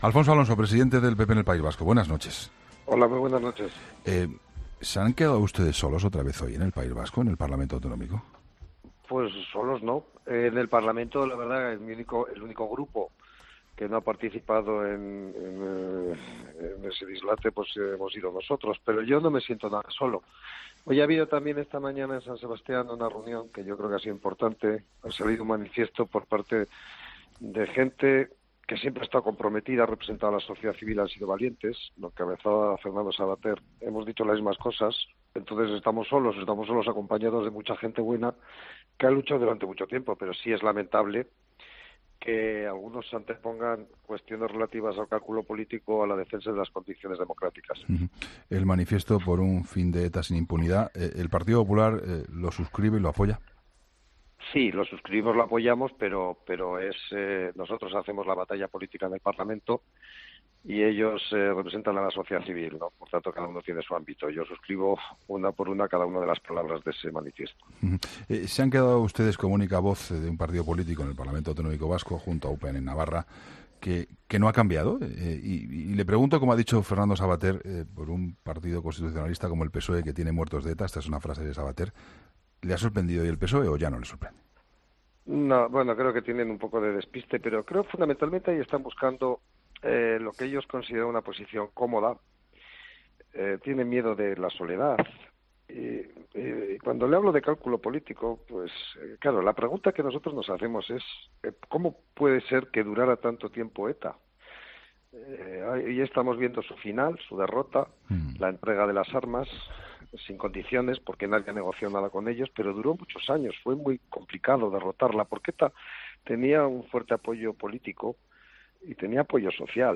Entrevista a Alfonso Alonso